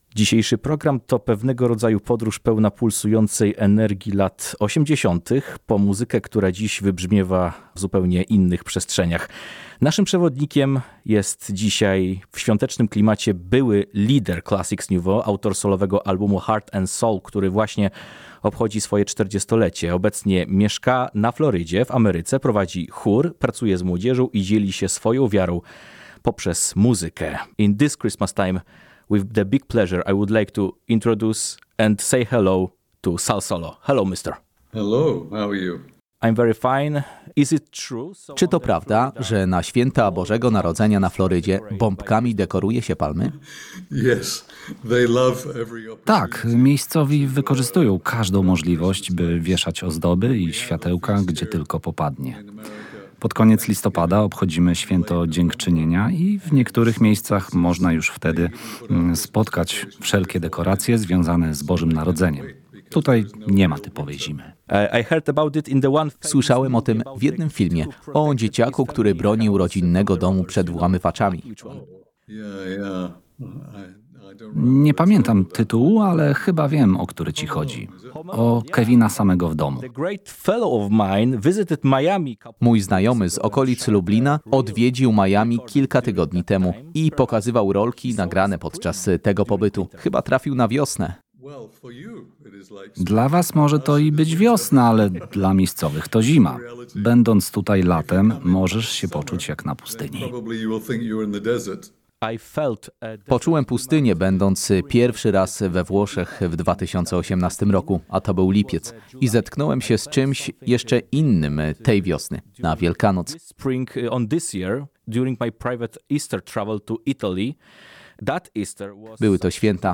Sal Solo - wywiad z okazji 40-lecia płyty Heart & Soul